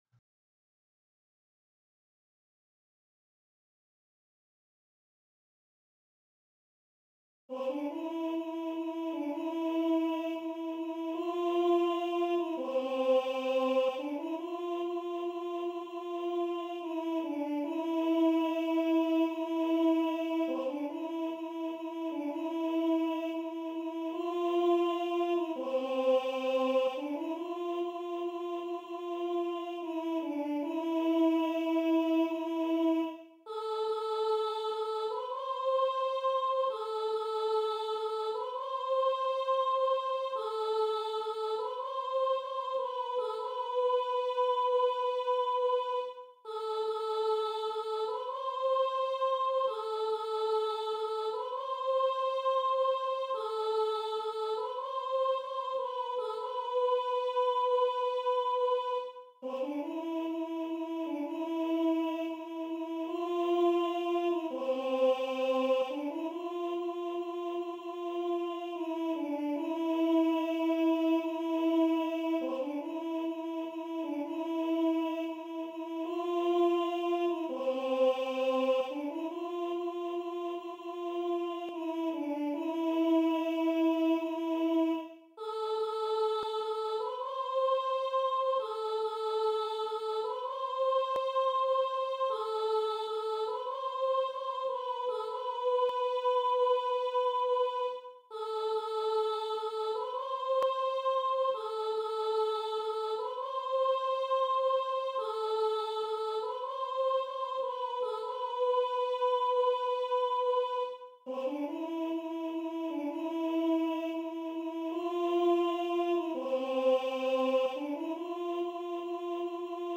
Répétition SATB par voix
Soprane
Regarde l'Etoile. Voix Soprane.mp3